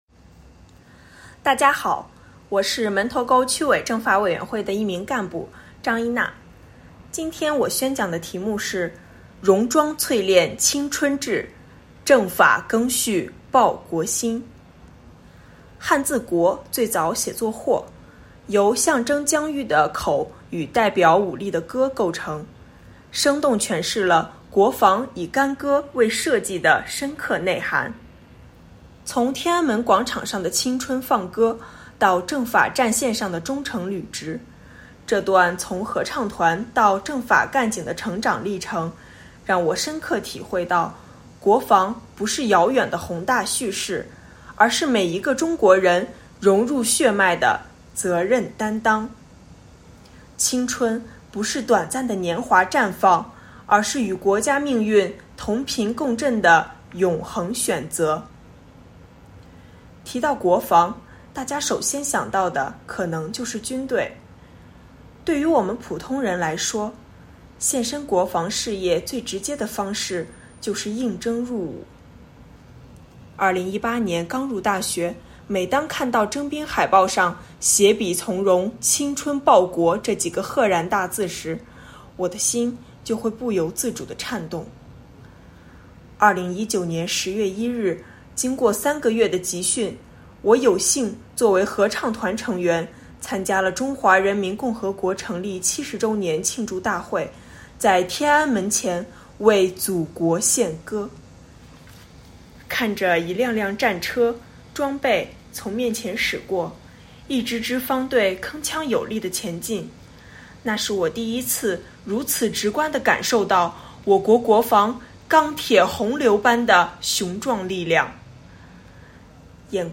“老兵永远跟党走”网络宣讲——赓续红色血脉 续写军旅荣光【第二十九期】